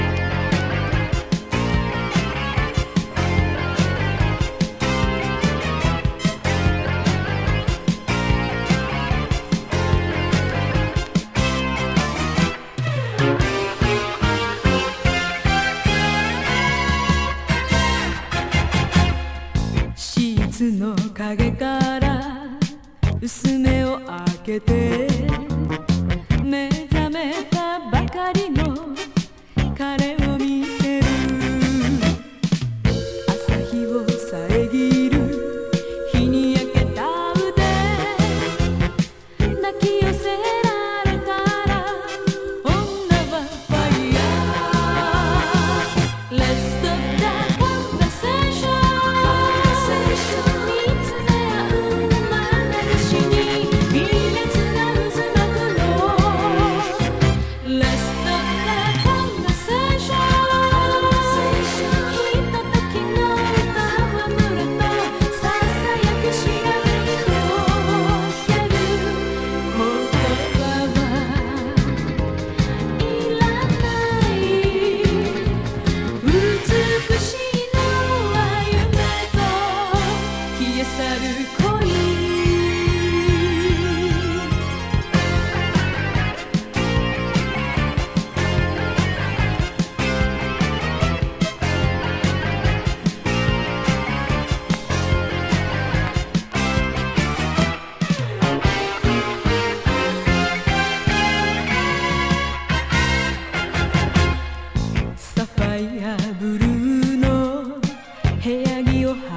アダルトな空気管漂わせるロッキン・ディスコチューンの2曲!! スペイシーな展開もありナイス!!
CITY POP / AOR